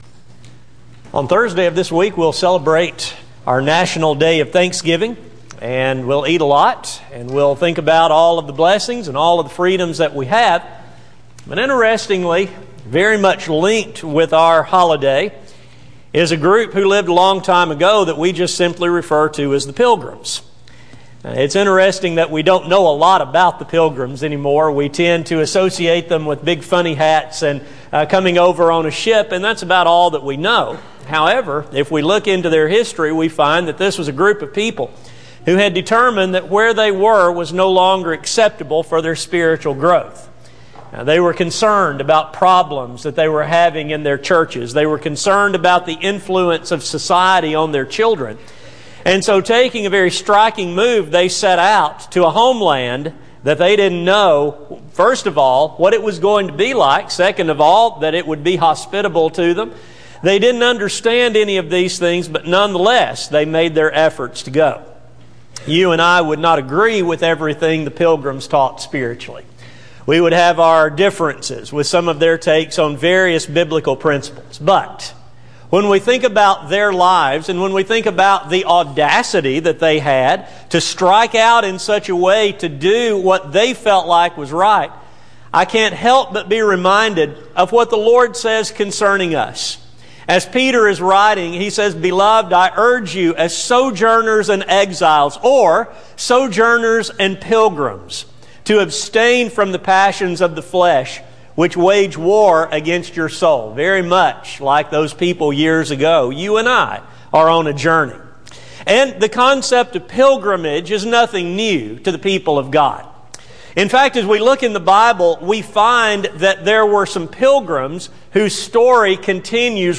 Service: Sun AM Type: Sermon